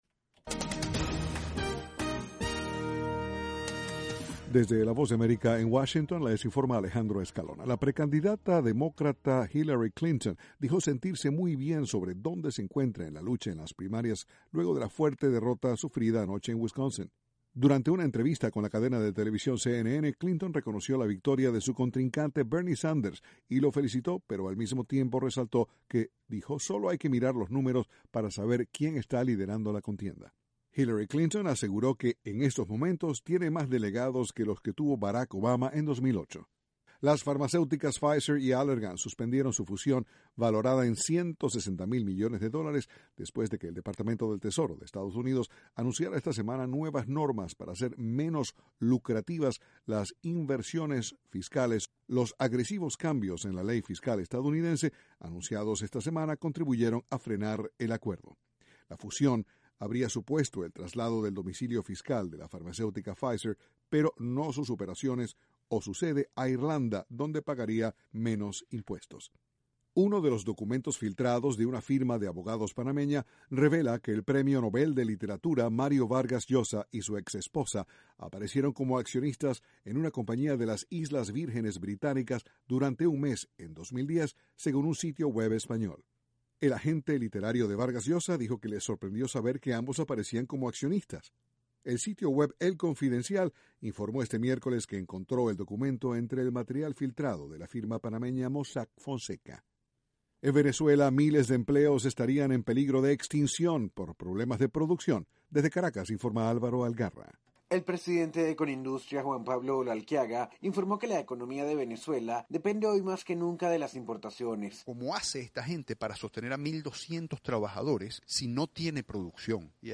VOA: Noticias de la Voz de América, Washington.